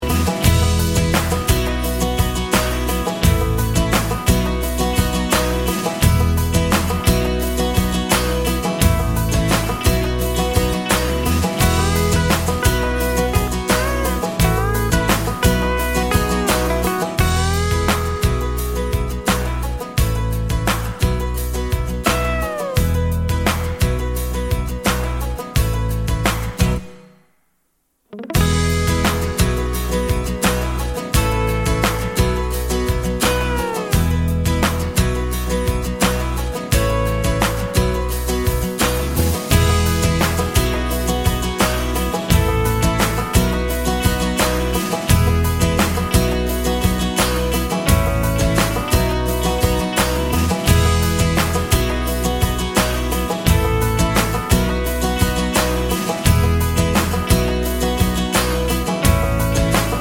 no Backing Vocals Country (Male) 2:49 Buy £1.50